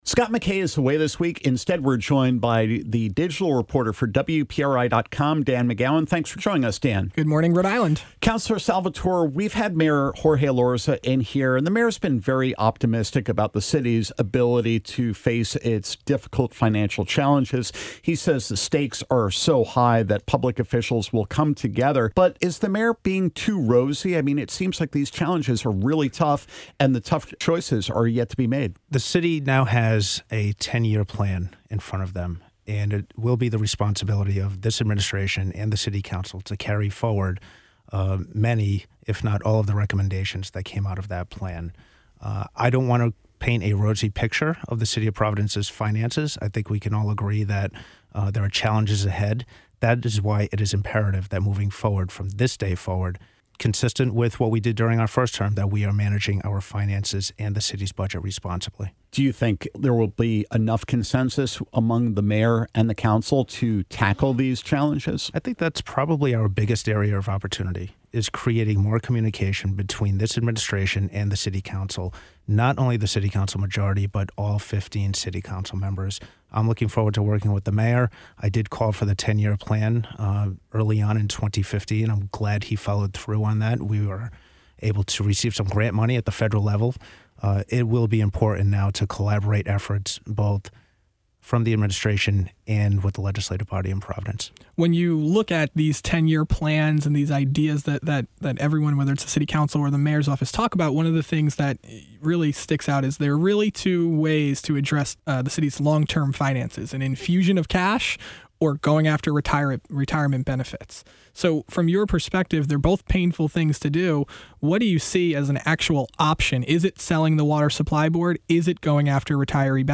Ward 14 Providence Councilor David Salvatore joins Bonus Q&A to talk about city finances, the proposed agreement with city firefighters, whether Providence’s influence at the legislature is waning, and other topics.